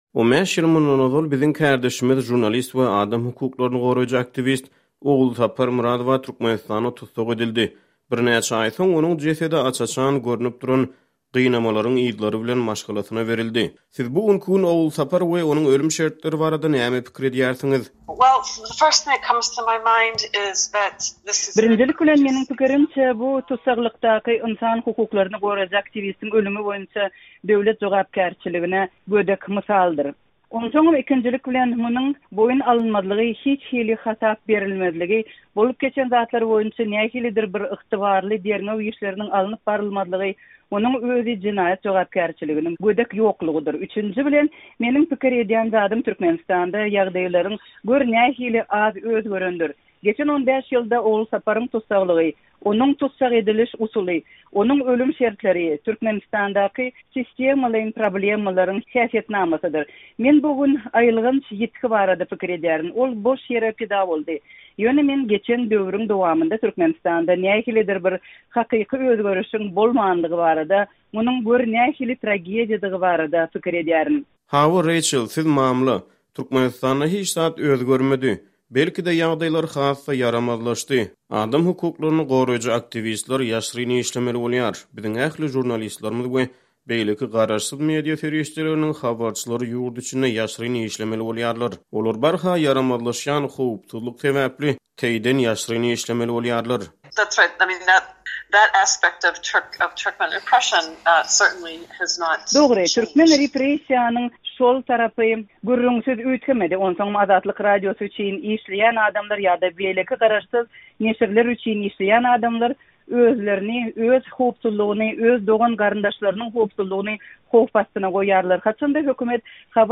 söhbetdeşlik